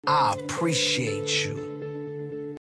motivational-speech-dj-khaled-another-one-you-smart-you-loyal-i-appreciate-you.mp3